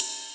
cymbal 4.wav